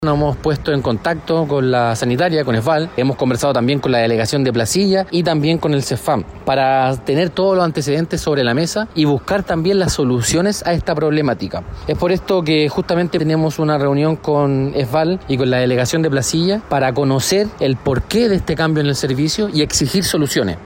Por su parte, el concejal Vicente Celedón, presidente de la Comisión de Salud, Deporte y Medioambiente de la Municipalidad de Valparaíso, indicó que se reunirán este jueves con las autoridades competentes para conocer antecedentes sobre la problemática.